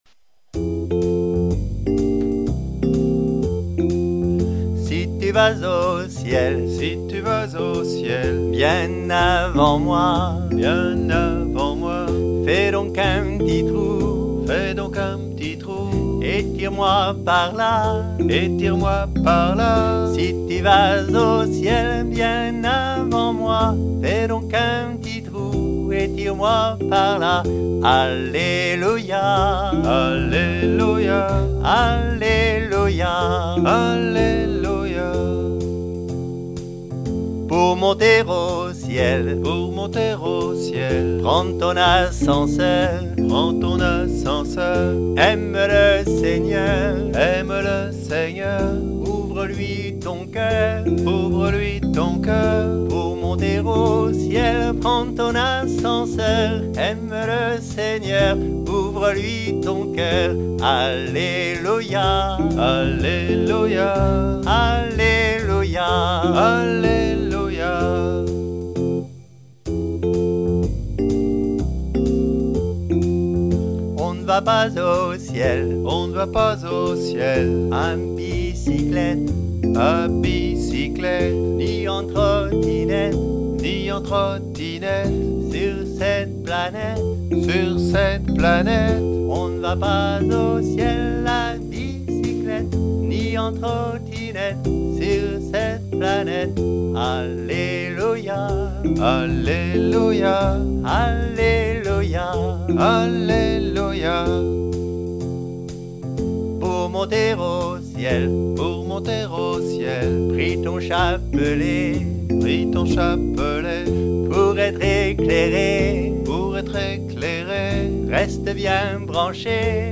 nous invite en musique
chant
il nous le dit d’une façon pleine d’humour